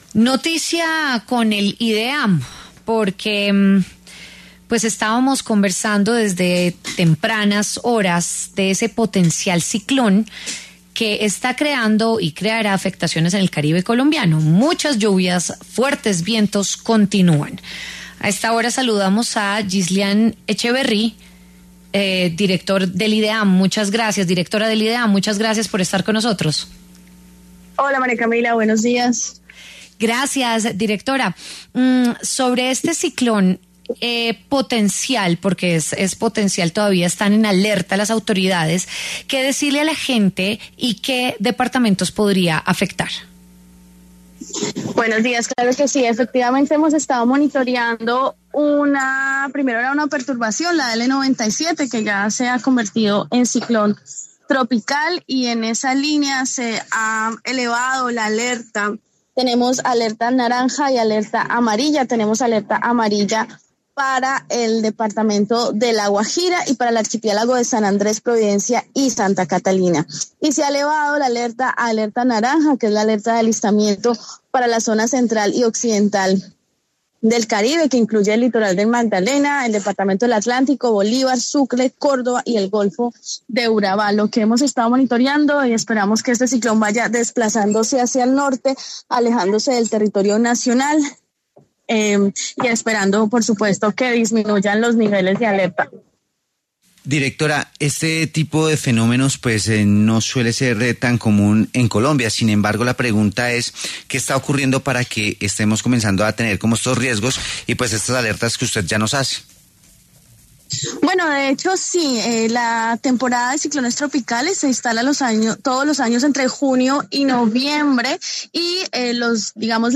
A propósito de esto, Ghisliane Echeverry, directora del Ideam, conversó en los micrófonos de La W sobre los efectos del potencial ciclón en el Caribe colombiano.